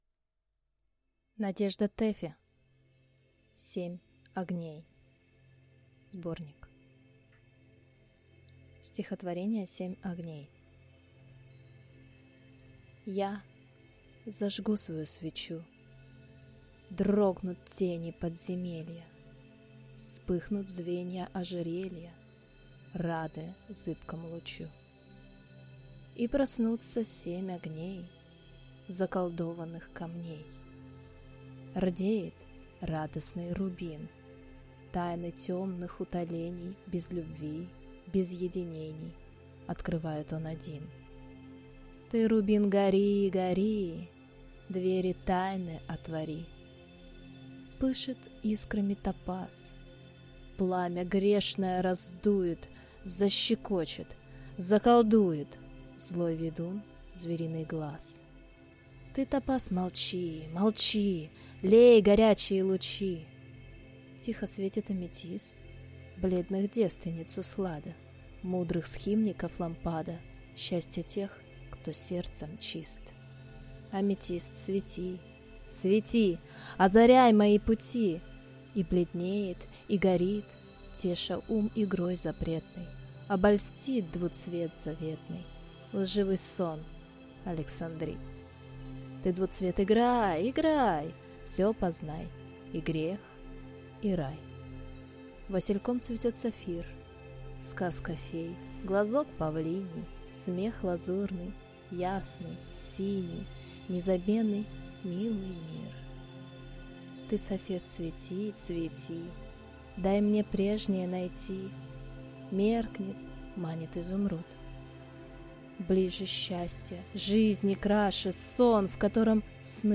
Аудиокнига Семь огней (сборник) | Библиотека аудиокниг